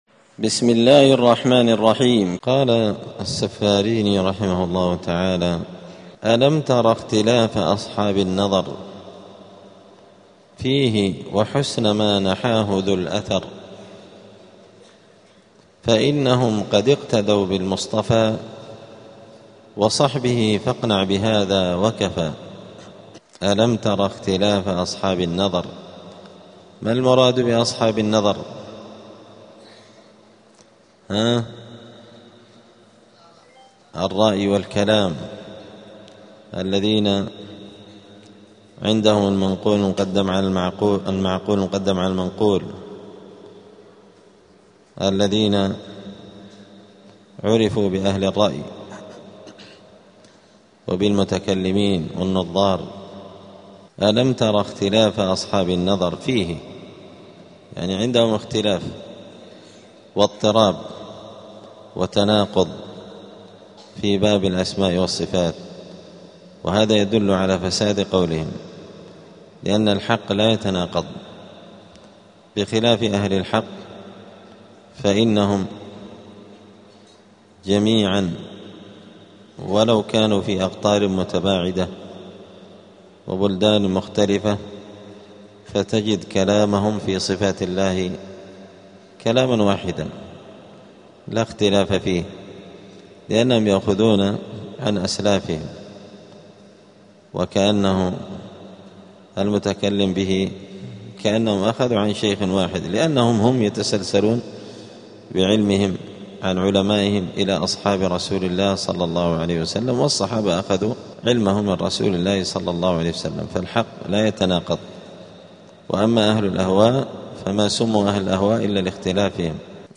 دار الحديث السلفية بمسجد الفرقان قشن المهرة اليمن
27الدرس-السابع-والعشرون-من-شرح-العقيدة-السفارينية.mp3